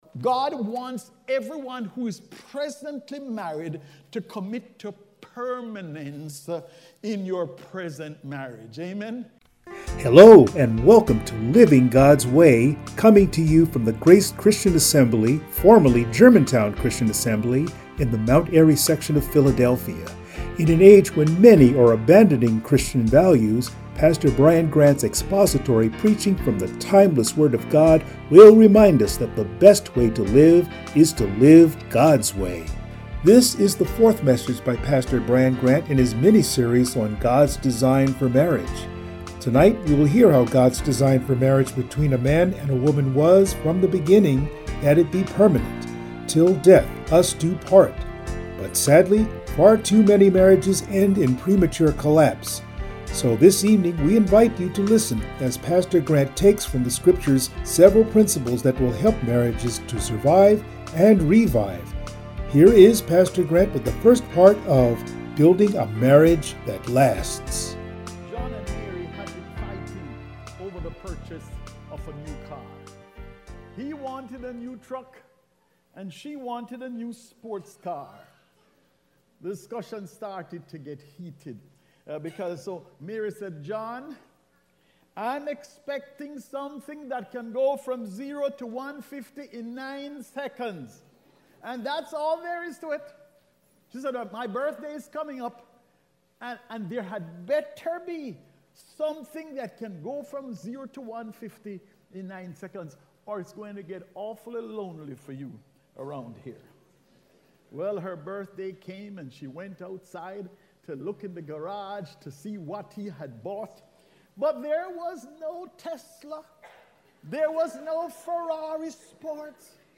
Passage: Matthew 7:21-28 Service Type: Sunday Morning